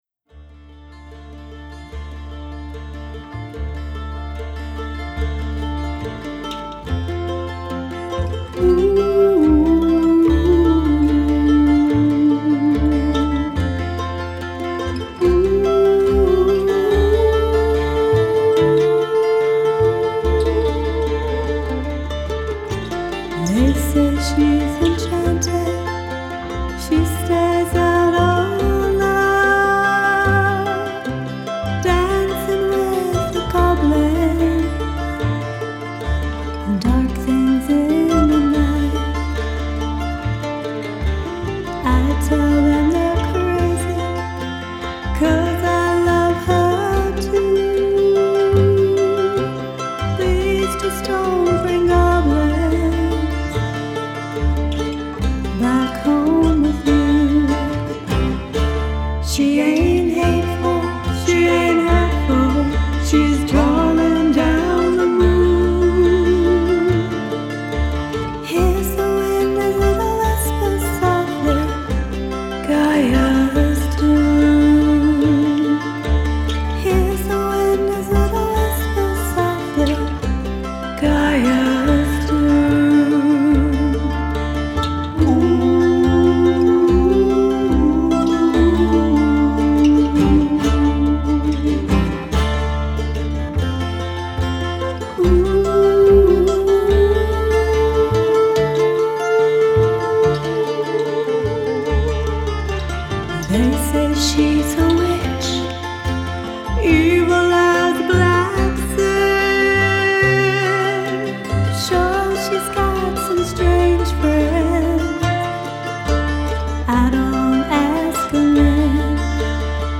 Folky Celtic inspired